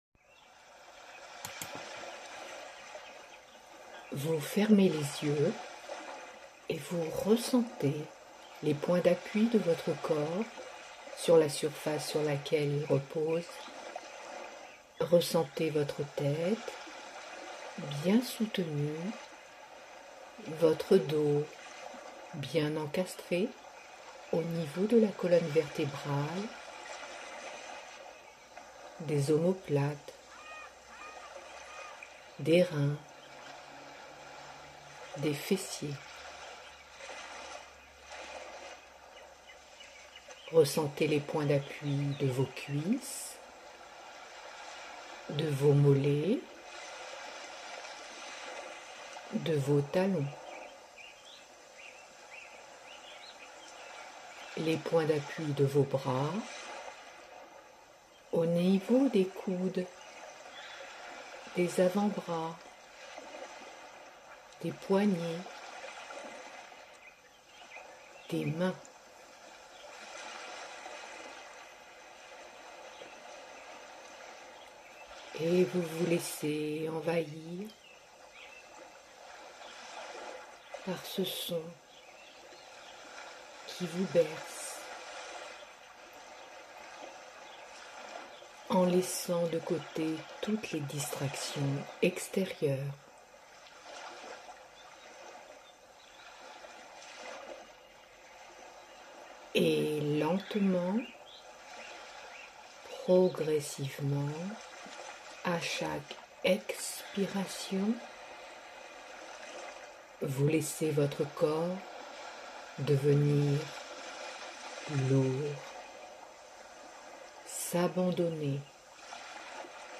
Séances de sophrologie | Espace Libellule - Association de lutte contre le cancer